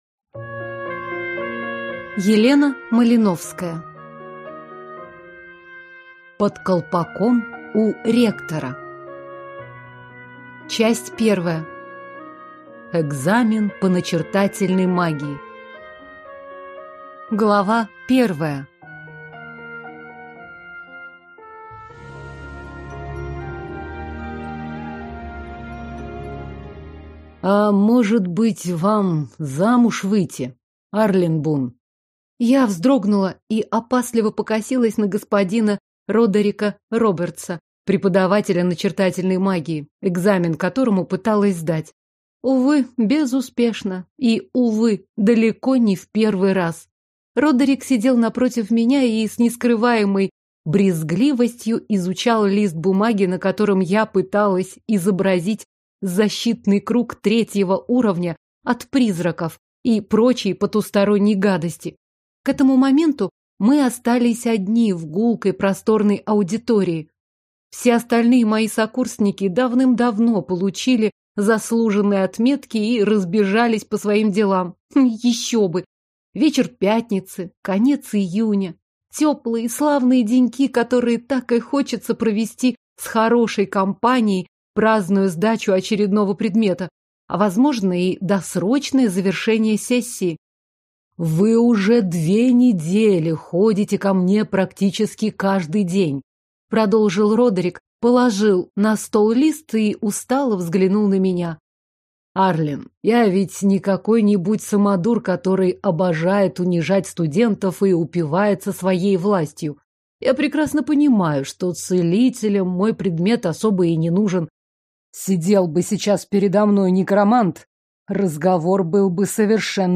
Аудиокнига Под колпаком у ректора | Библиотека аудиокниг